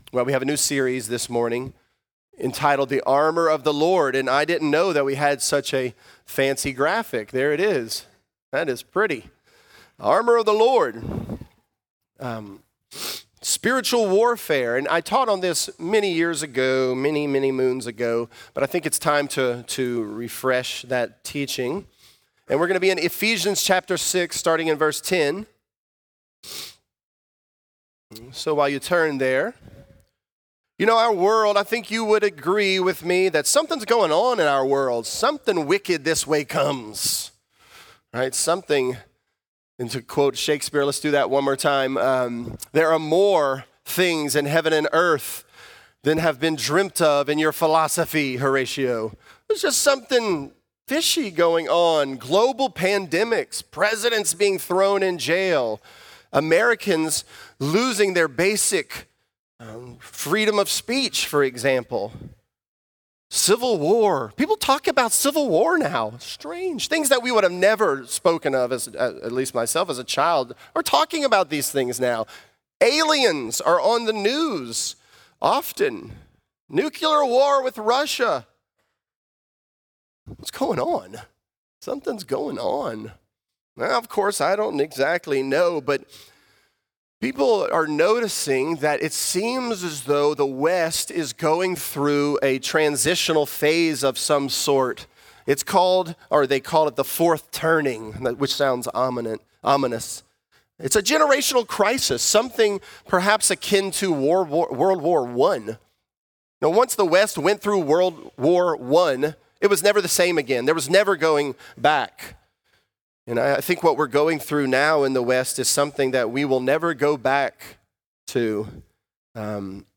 This is a part of our sermon series.